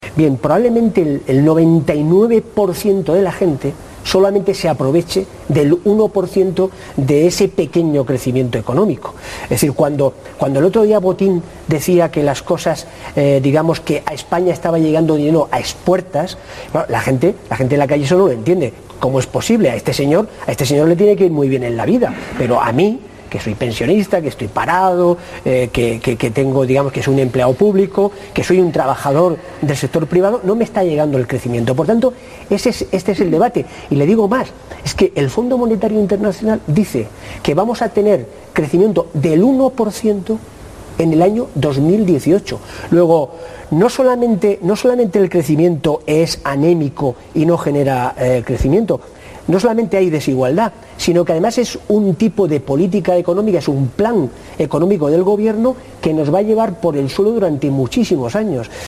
Fragmento de la entrevista a Pedro Saura en La Tarde en 24 horas, de TVE. 23/10/13